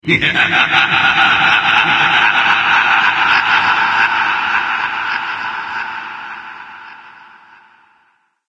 Гул, стоны, скрежет и другие жуткие эффекты создадут атмосферу мистики или станут основой для творческих проектов.
Смех дьявола при виде нового грешника